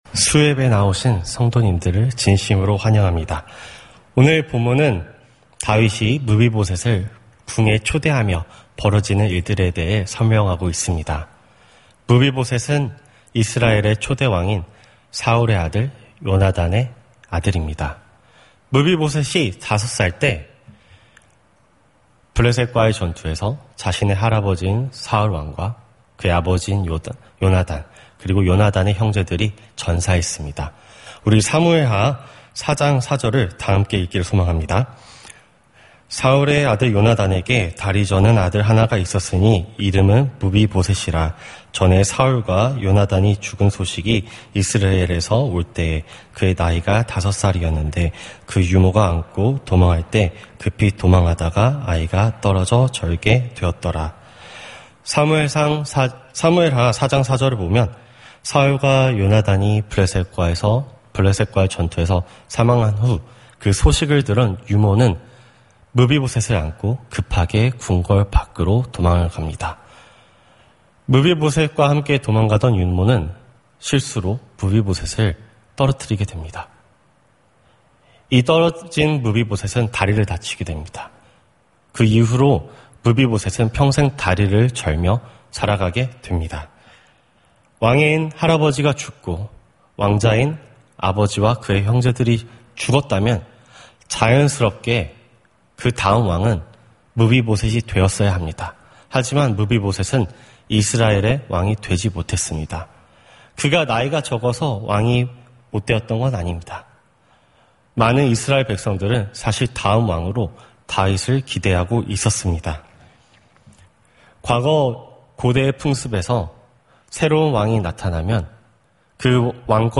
수요예배